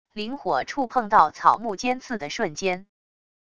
灵火触碰到草木尖刺的瞬间wav音频